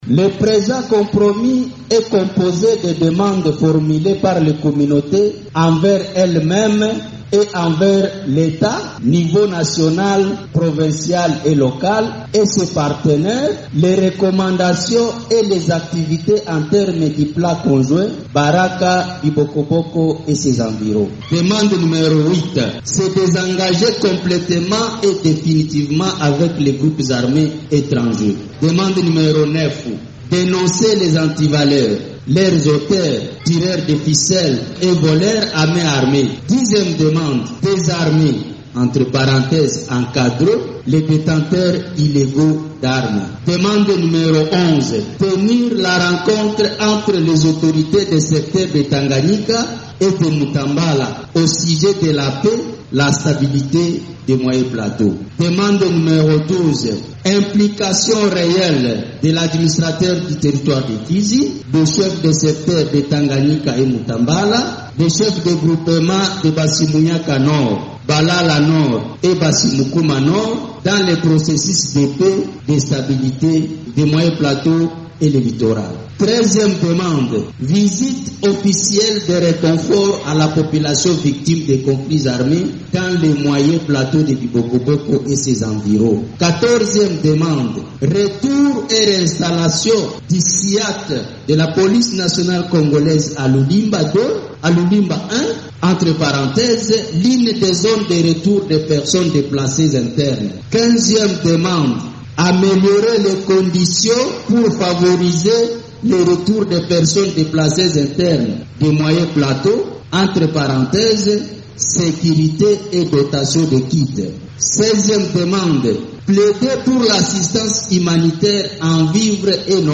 ELEMENT-DE-REPORTAGE-COMPROMIS-DIALOGUE-FIZI-FR.mp3